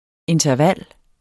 Udtale [ entʌˈvalˀ ]